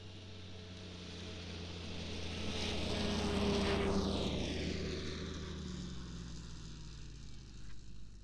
Compression Ignition Snowmobile Description Form (PDF)
Compression Ignition Subjective Noise Event Audio File - Run 4 (WAV)